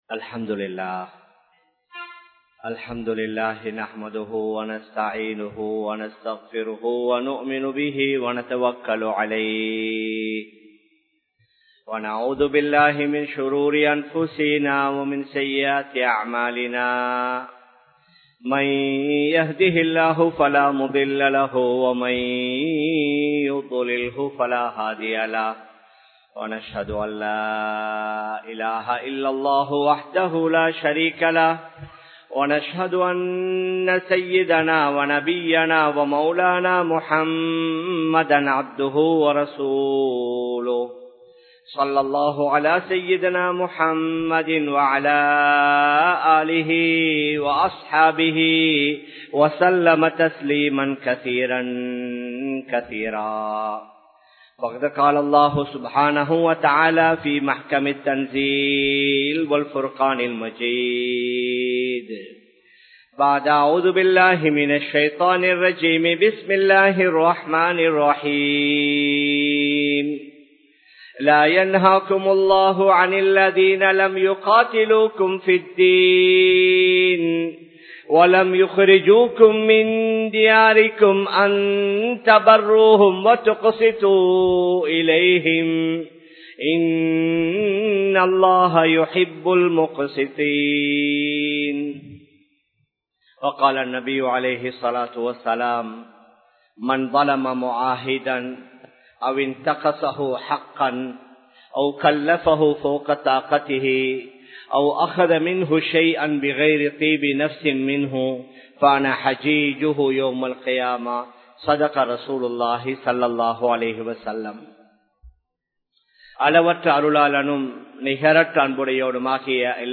Ottrumaiyaaha Vaalvoam (ஒற்றுமையாக வாழுவோம்) | Audio Bayans | All Ceylon Muslim Youth Community | Addalaichenai
Ar Rahmath Jumua Masjidh